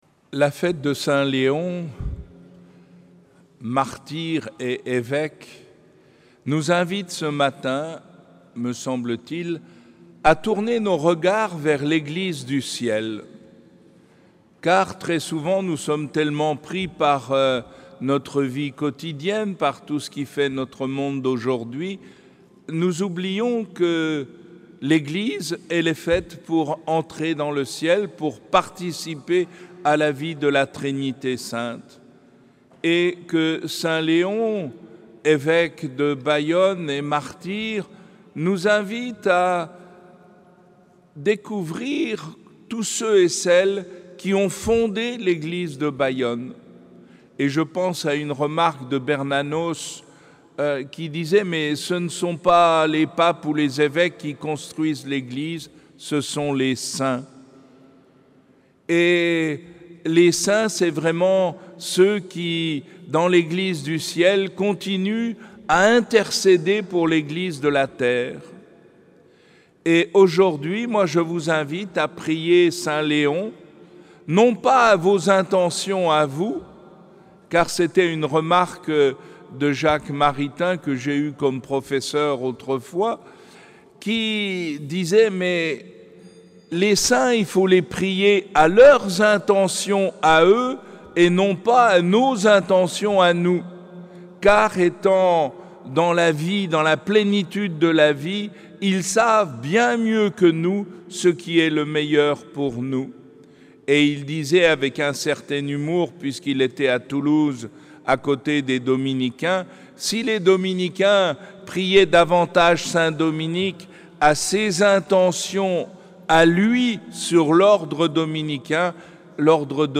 Homélie de Mgr Jean-Pïerre Cattenoz, archevêque émérite d’Avignon en la cathédrale Sainte-Marie de Bayonne.